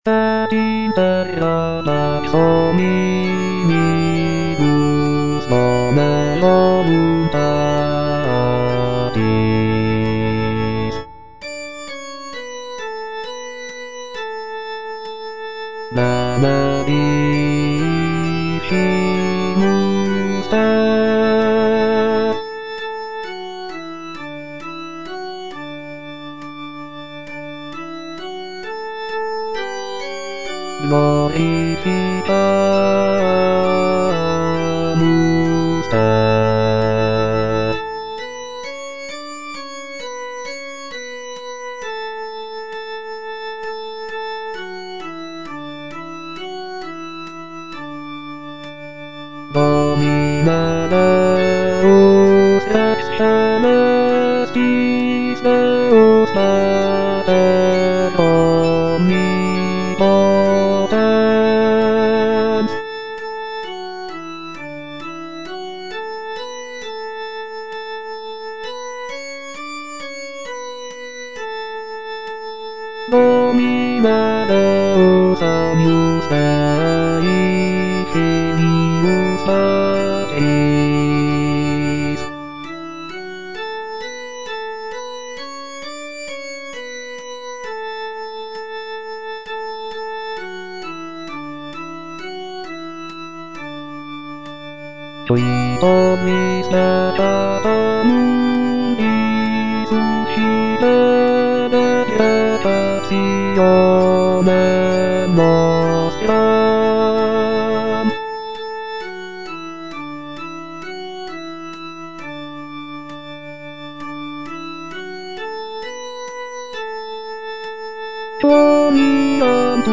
BASSES